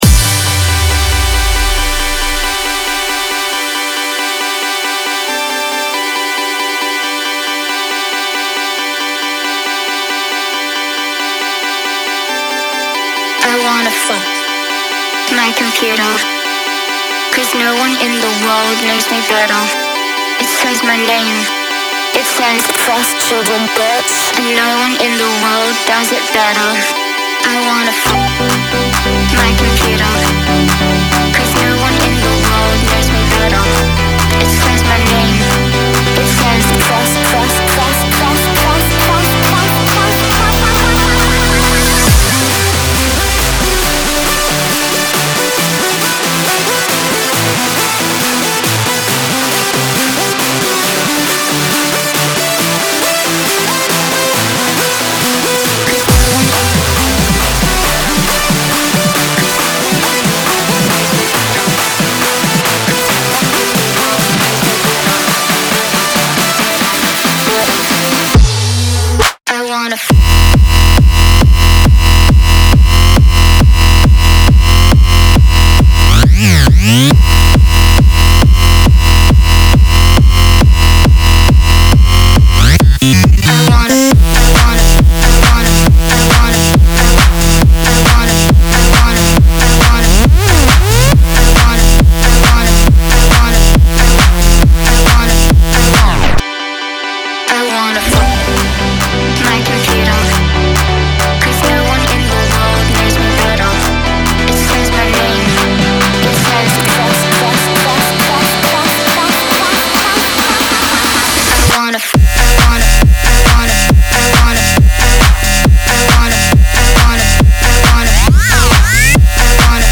BPM137-137
Audio QualityPerfect (High Quality)
Full Length Song (not arcade length cut)